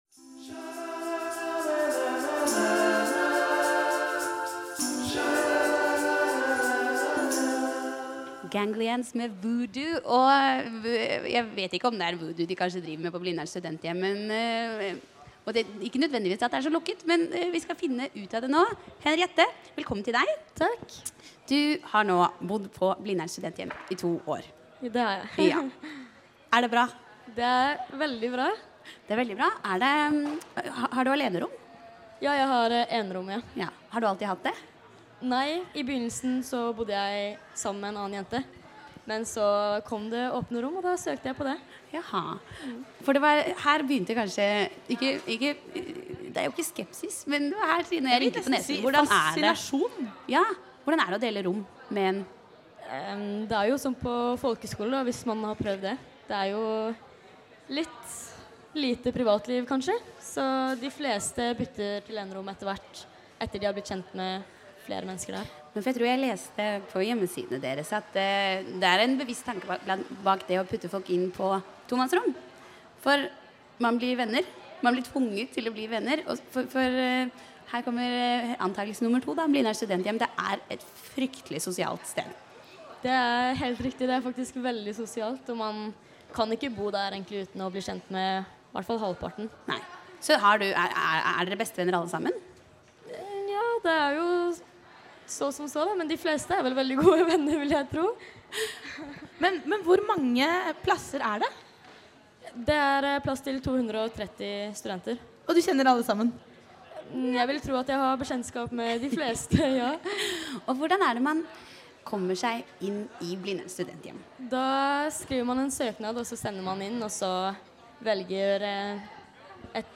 INTERVJU PÅ RADIO NOVA